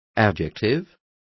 Complete with pronunciation of the translation of adjectives.